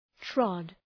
Προφορά
{trɒd}